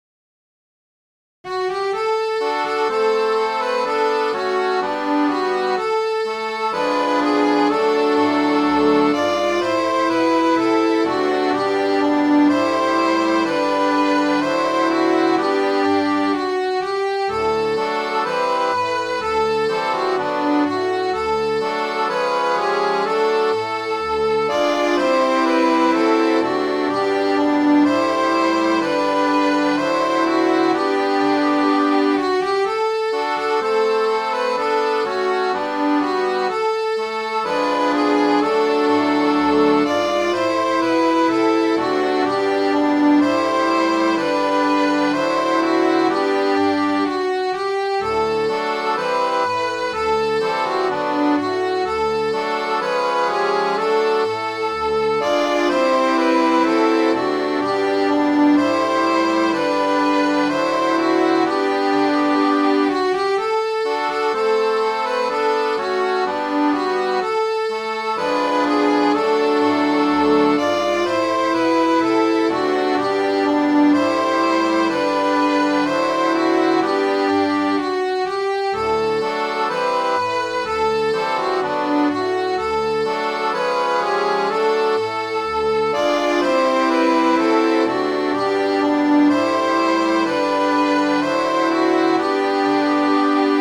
Midi File, Lyrics and Information to One More Day